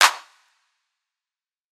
Clap - Storch.wav